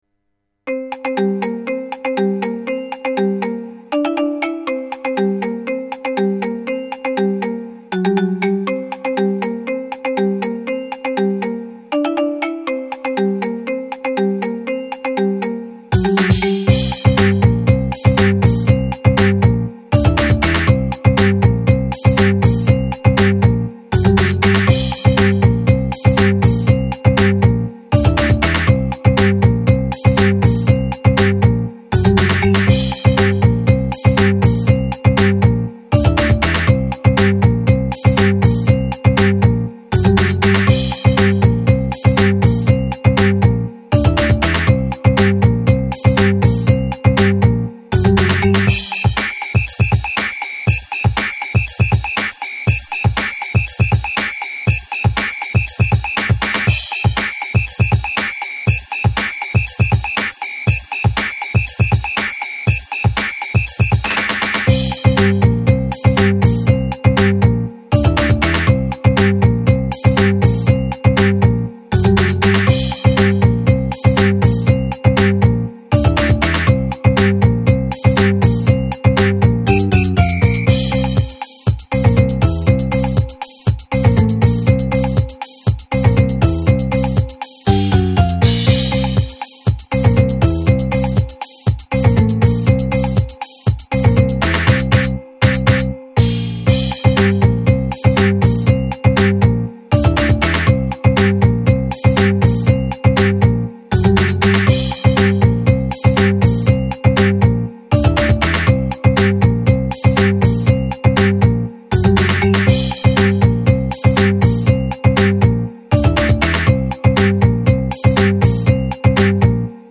本分类为搞笑·童话系，为您准备了从奇幻风格到明快风格的曲目。